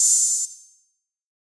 DDW3 OPN HAT 2.wav